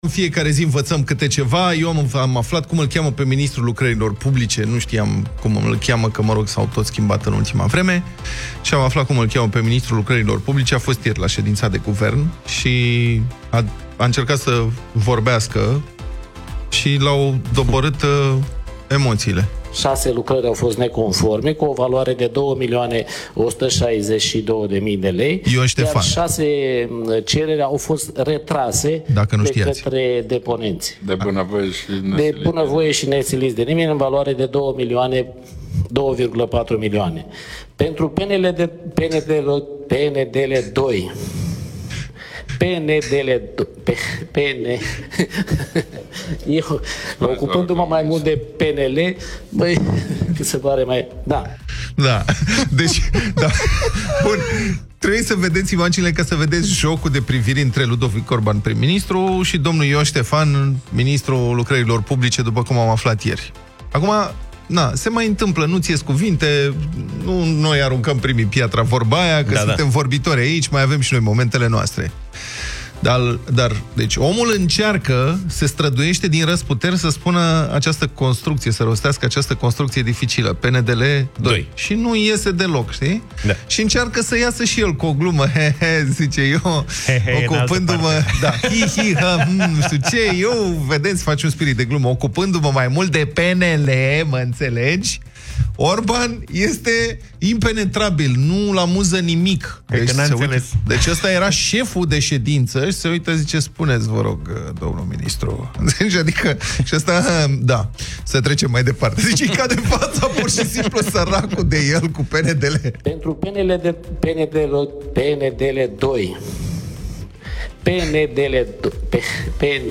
Bâlbele ministrului Dezvoltării, care nu poate spune PNDL 2 (AUDIO)
Emoții mari pentru ministrul Dezvoltării și Lucrărilor Publice, Ion Ștefan, în ședința de guvern când s-a chinuit să pronunțe cuvântul PNDL.